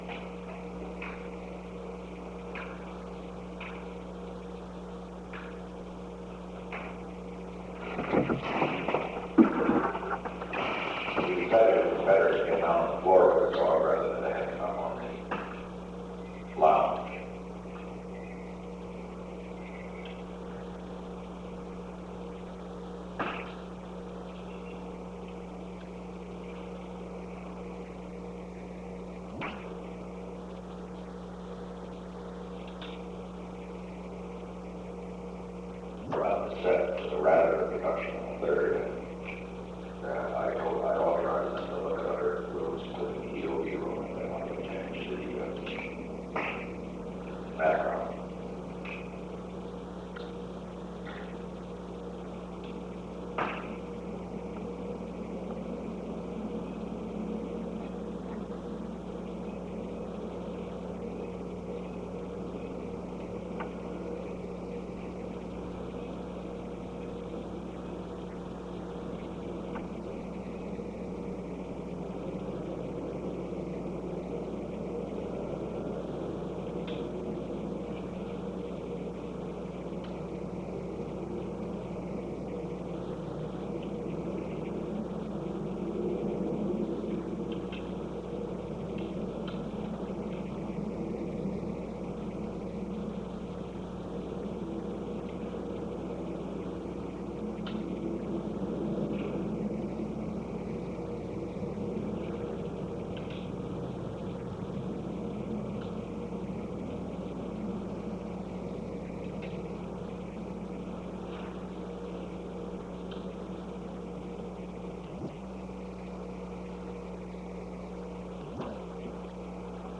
Secret White House Tapes
Conversation No. 637-7
Location: Oval Office
The President dictated a memorandum for the file [?]